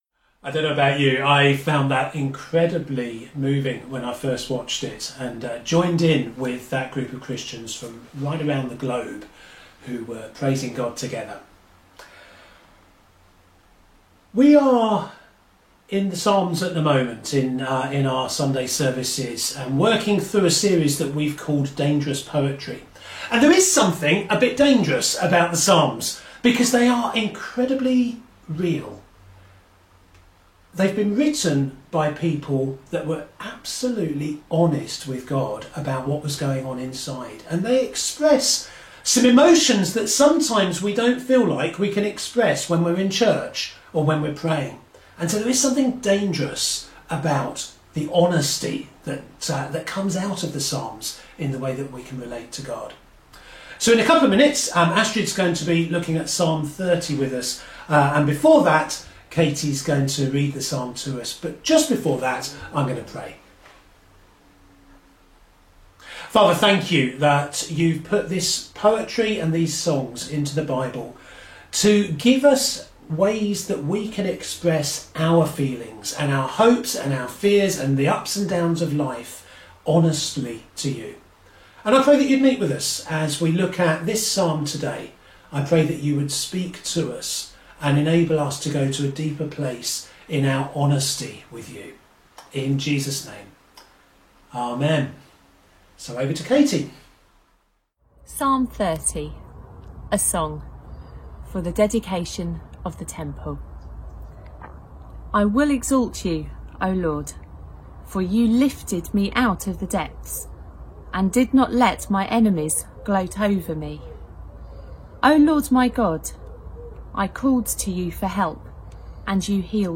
July 19th sermon.mp3